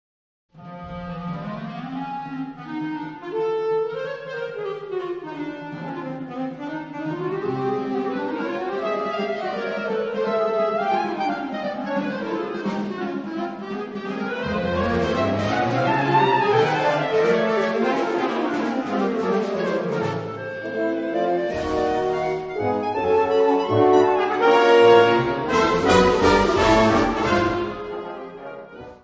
Kategorie Blasorchester/HaFaBra
Unterkategorie Zeitgenössische Bläsermusik (1945-heute)